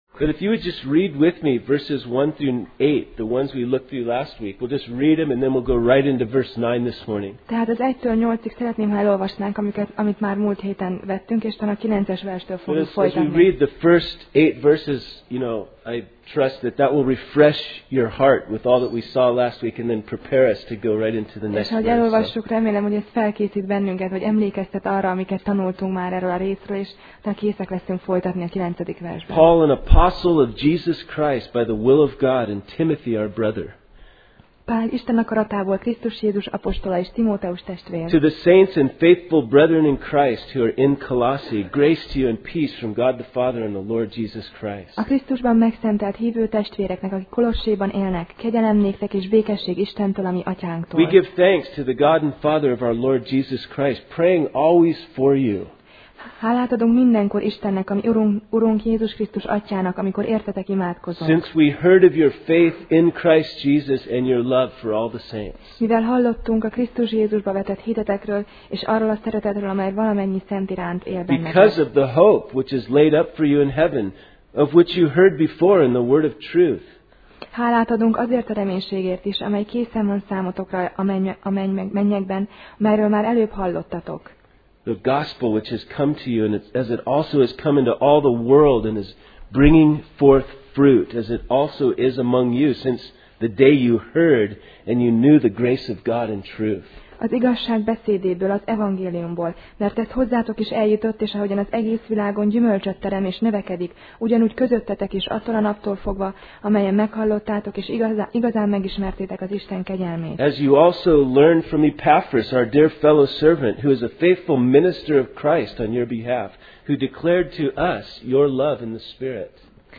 Kolossé Passage: Kolossé (Colossians) 1:9-10 Alkalom: Vasárnap Reggel